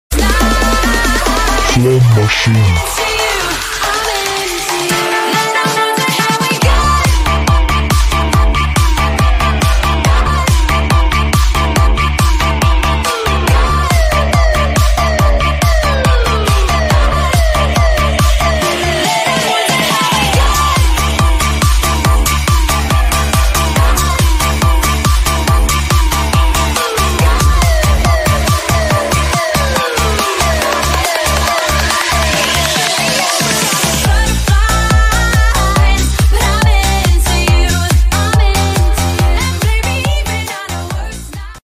Moka sfx flame show 🔥 sound effects free download